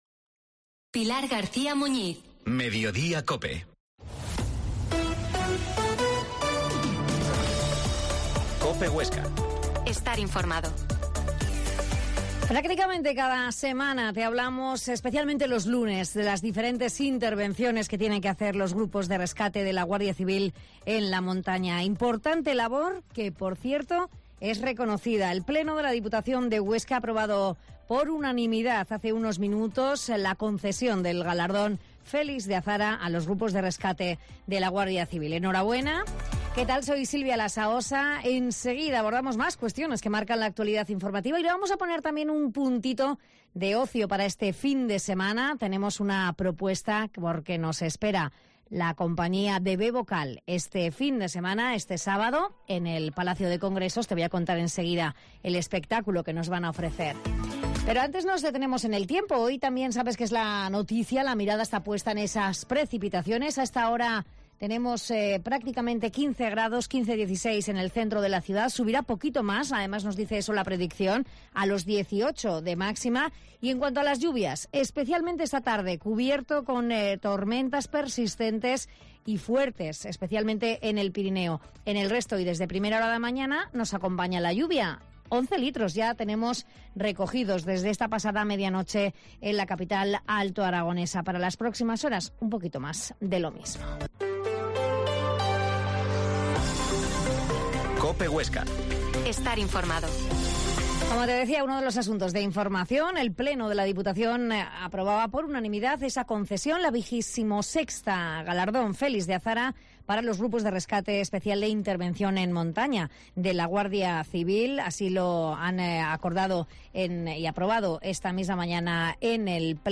Mediodia en COPE Huesca 13.50h Entrevista al integrante del grupo B VOCAL